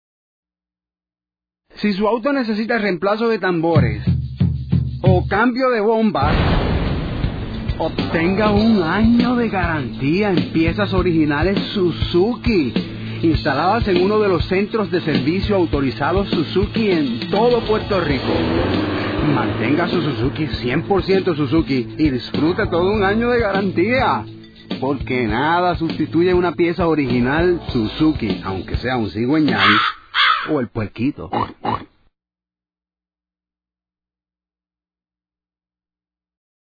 To promote the selling of auto parts in a novel and memorable way was a challenge we enjoyed. Using the funny names of some parts and related sound effects we carried the message across that Suzuki is the only place to get original auto parts.